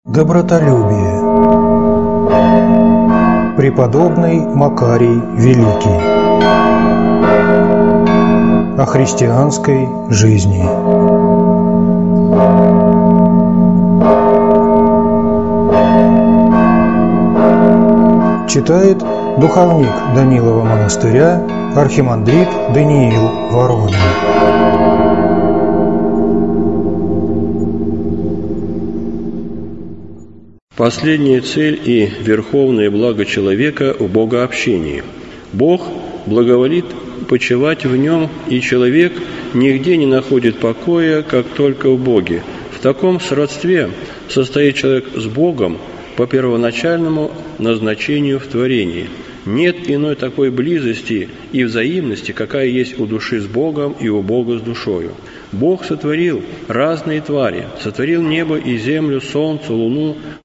Аудиокнига О христианской жизни | Библиотека аудиокниг